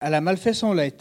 Collectif patois et dariolage
Catégorie Locution